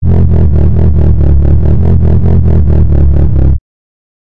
描述：Dubstep低音循环，在140 bpm.
标签： 低音 的dubstep 循环 SUB 摆动
声道立体声